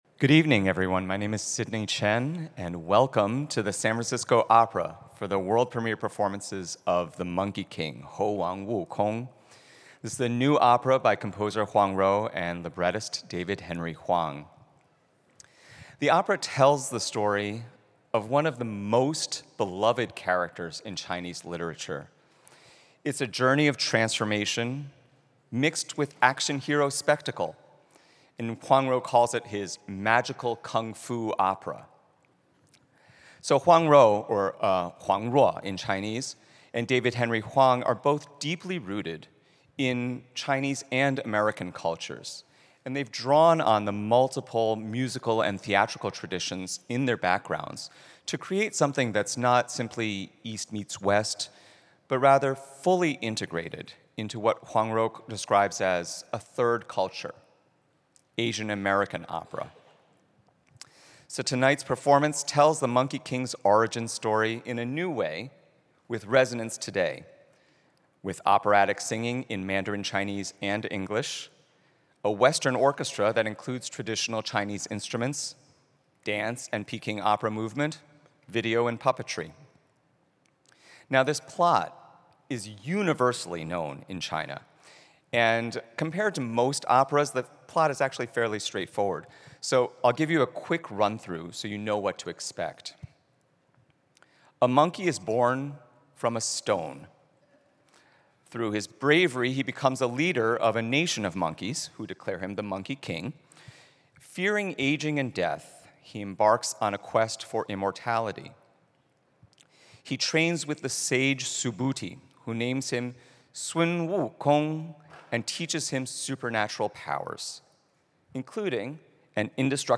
monkey_king_pre-show_lecture.mp3